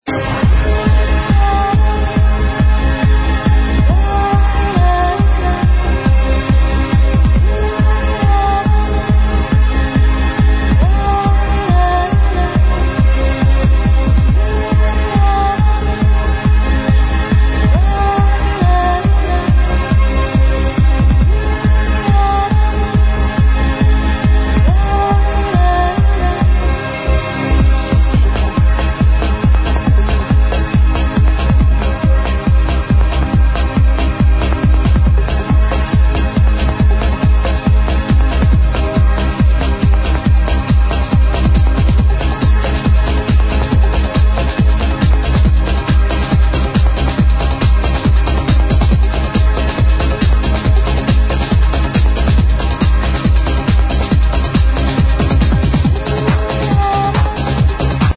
AGAIN!!!>..gets distorted and stops playing at 30 seconds...